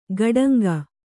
♪ gaḍaŋga